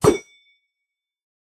Minecraft Version Minecraft Version 25w18a Latest Release | Latest Snapshot 25w18a / assets / minecraft / sounds / item / trident / throw2.ogg Compare With Compare With Latest Release | Latest Snapshot
throw2.ogg